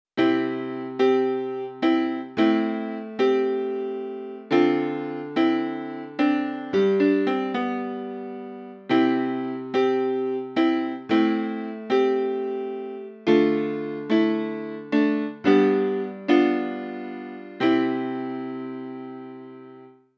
00 Piano 10 Melodic Percussion 18 Organ 29 Guitar 34 Bass 43 Strings 50 Trumpet 59 Wood 76 Synth 90 Indian Song aus Casio Sounds: 99 Drums, 38 Bass & Song aus Casio Sounds: 99 Drums, 37 Bass & 30 Gitarre Layer: 00 Piano & 47 Strings